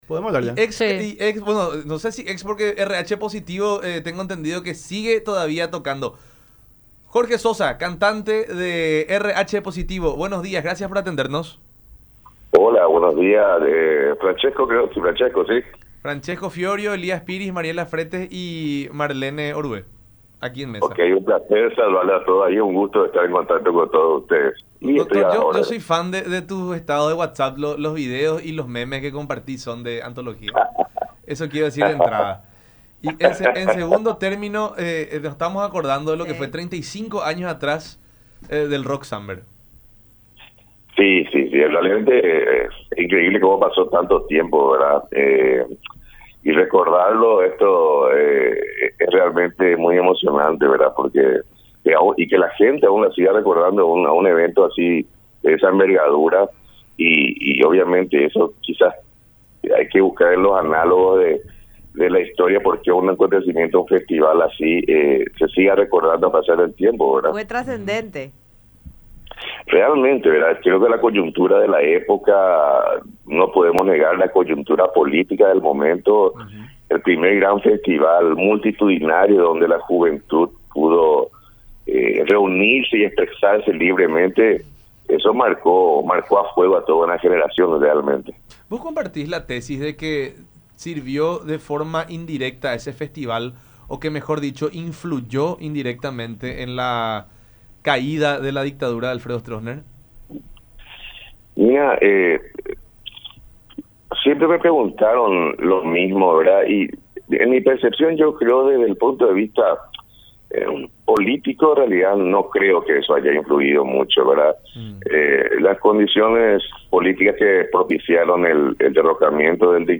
en conversación con La Unión Hace La Fuerza por Unión TV y radio La Unión.